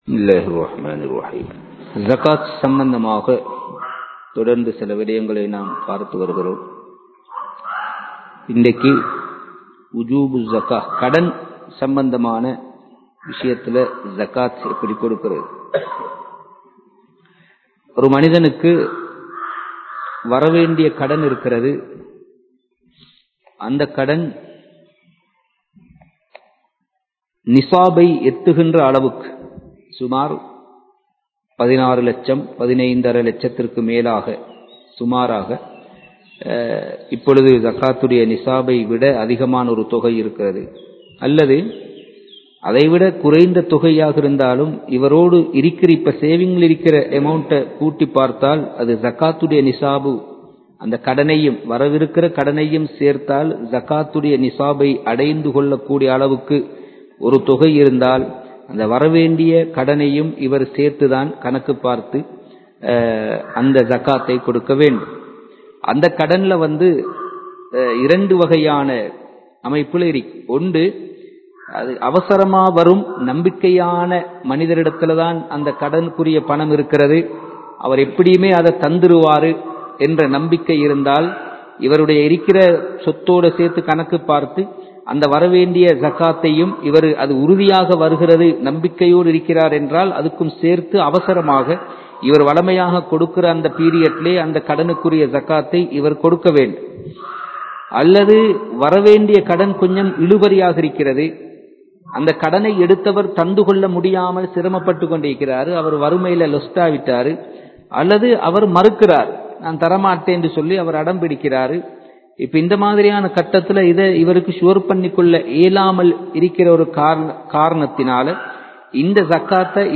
ஸகாத் (Thafseer Class 25) | Audio Bayans | All Ceylon Muslim Youth Community | Addalaichenai
Kandy, Kattukela Jumua Masjith